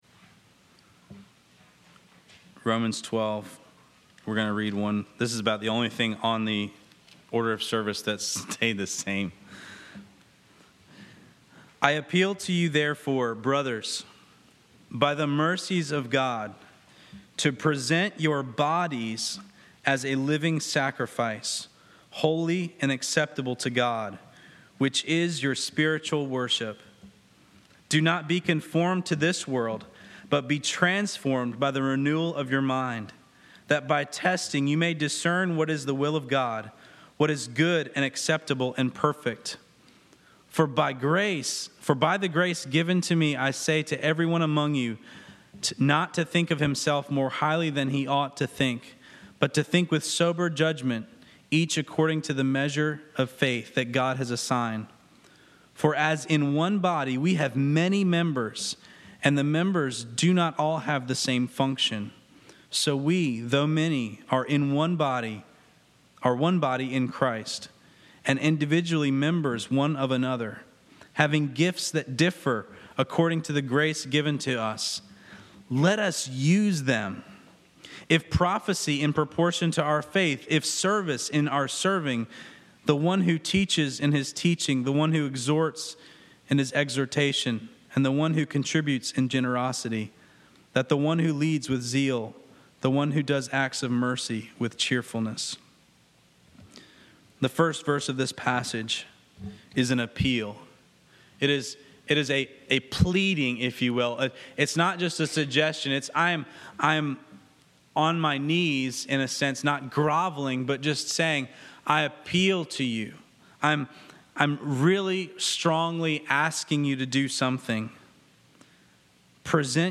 This week, we spent most of our Sunday service in song and prayer seeking God for this upcoming season.
I wanted to take this Sunday and really lay down our lives for God’s purpose for us. That translates to the shortest message I have even given at ECC.